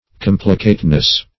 Complicateness \Com"pli*cate*ness\, n.